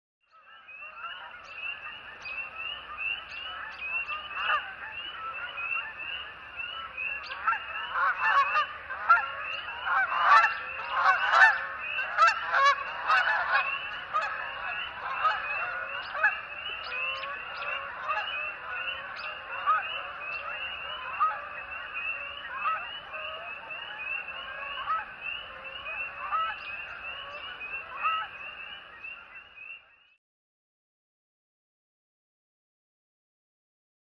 Canadian Geese Honk, Large Flock, Nice Moody Track.